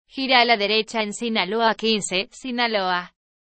Entidad Federativa Prefijo Ejemplo TTS (Ariane)